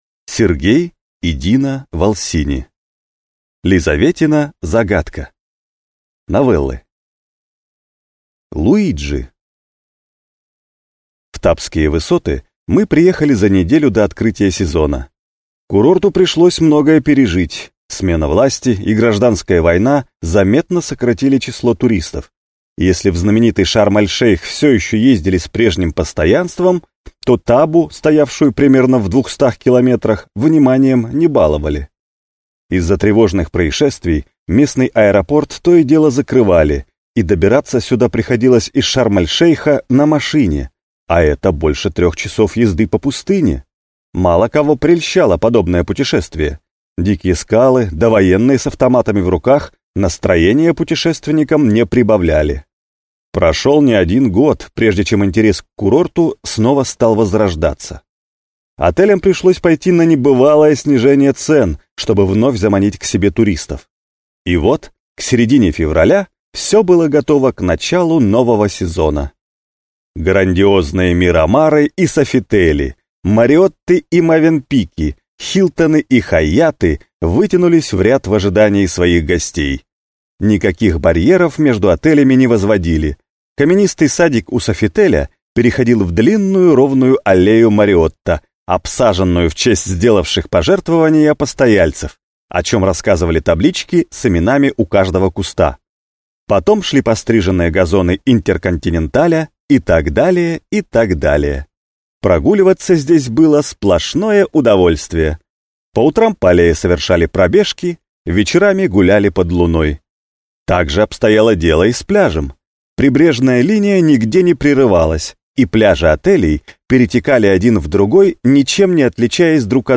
Аудиокнига Лизаветина загадка (сборник) | Библиотека аудиокниг